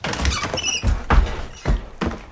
门打开
描述：一扇吱吱作响的木门打开。
Tag: 打开 吱吱 吱吱声 木材 吱吱作响